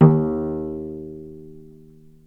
vc_pz-D#2-ff.AIF